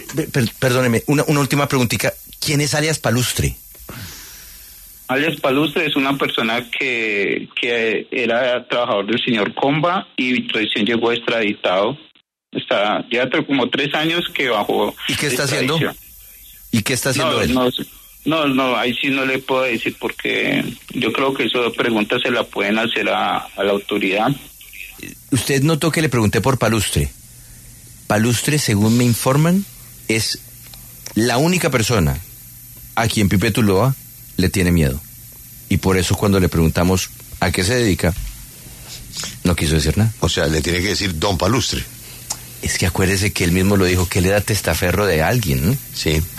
Sin embargo, se negó a entregar más información remitiendo a los periodistas de W Radio a las autoridades.